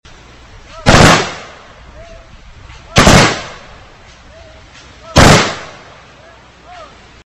In addition, the ceremony featured a reading of names for veterans who died over the past year, a 21-gun salute and Taps.
1680-memorial-day-volley.mp3